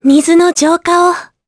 Laias-vox-select_jp.wav